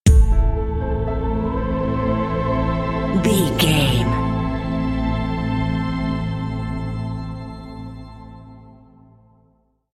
Aeolian/Minor
Slow
synthesiser
piano
percussion
drum machine